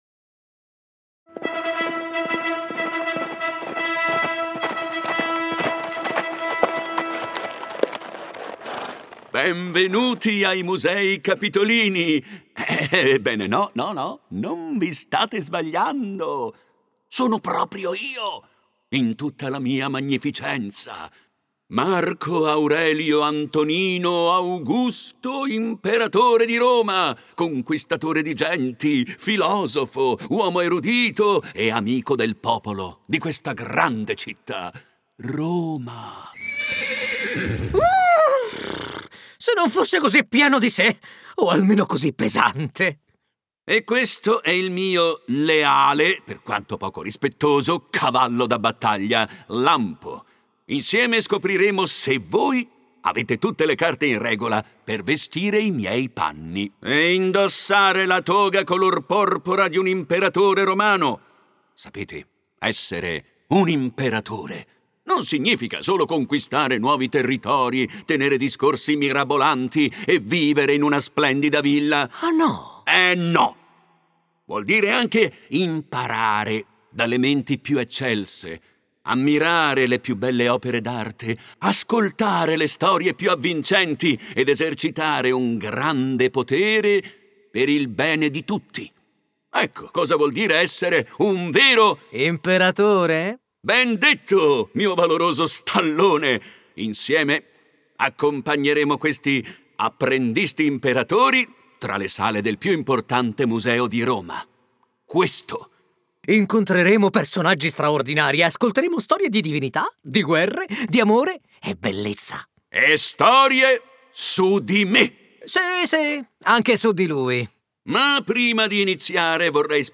Special audio guides for children and their families at the Musei Capitolini.
The great emperor Marcus Aurelius, with his cute and witty steed called Lampo, will welcome children in the Musei.